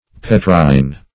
Petrine \Pe"trine\, a. Of or pertaining to St. Peter; as, the Petrine Epistles.